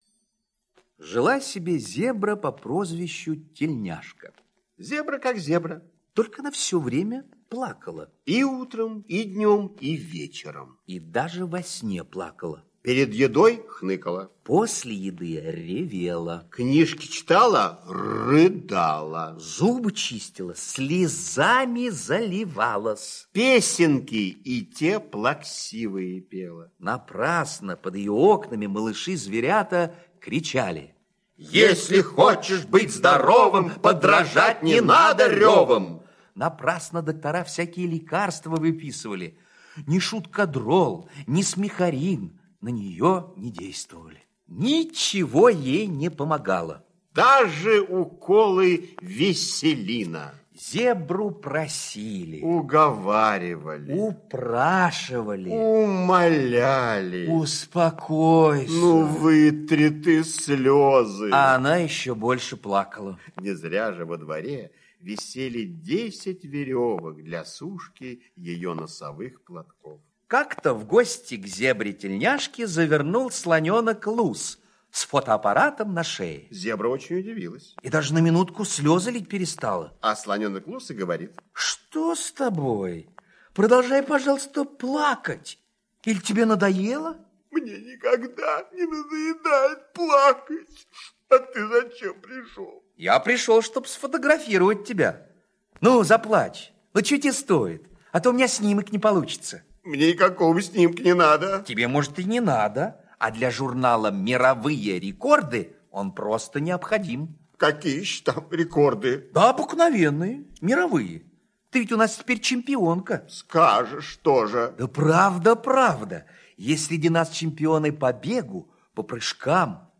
Слушайте Лечебный фотоаппарат - аудиосказка Пляцковского М.С. Сказка про зебру, которая все время плакала и никто не могли помочь.